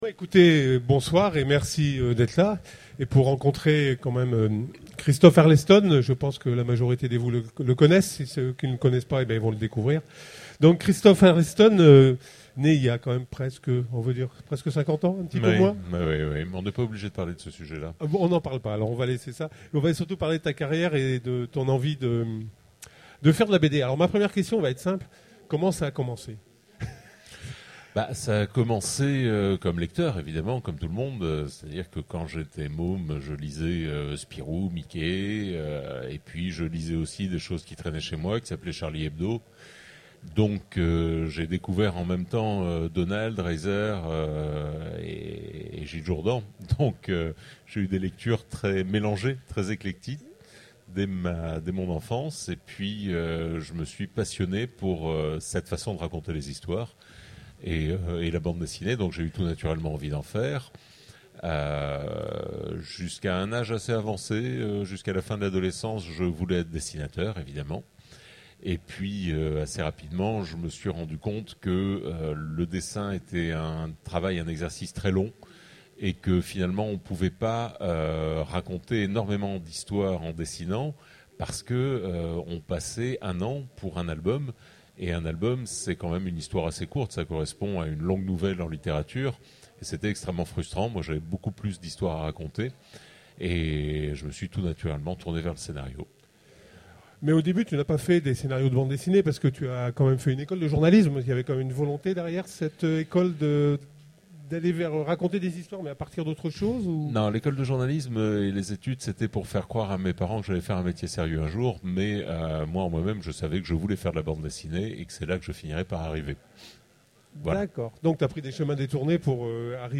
Utopiales 12 : Conférence Rencontre avec Arleston